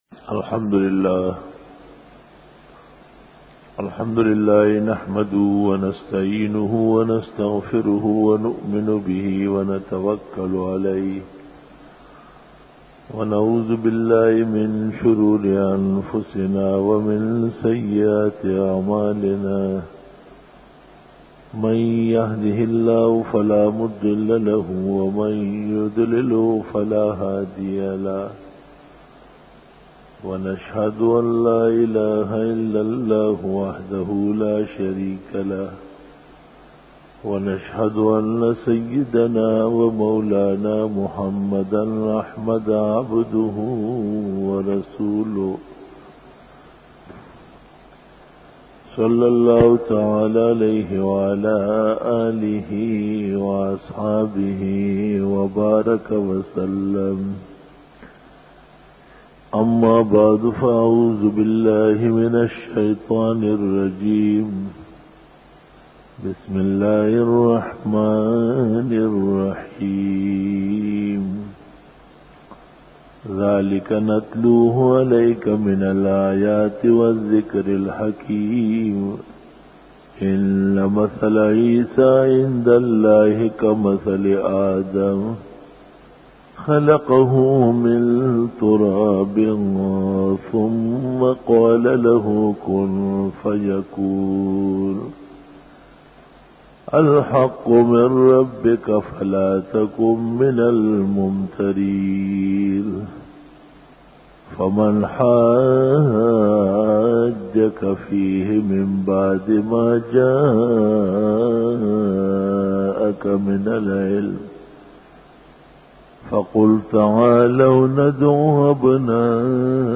011_Jummah_Bayan_22_Mar_2002
بیان جمعۃ المبارک